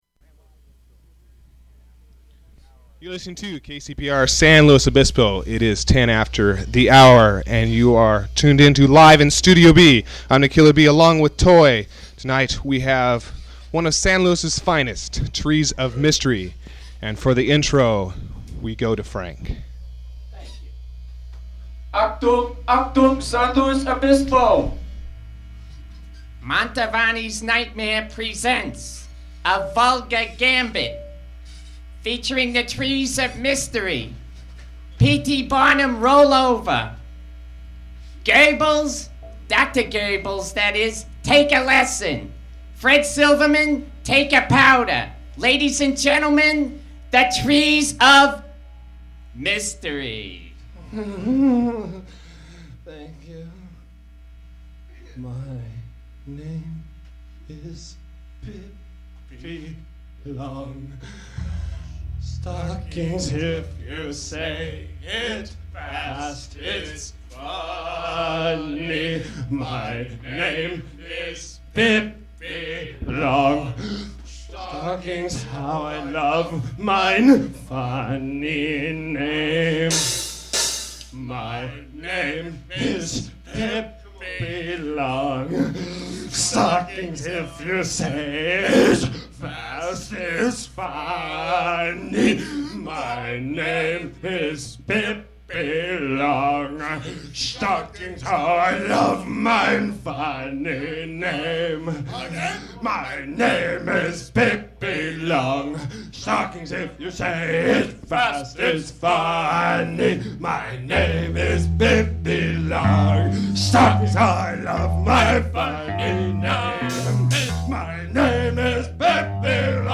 Station tag, brief question-and-answer
someone aggressively squeaks a chair in the background
Open reel audiotape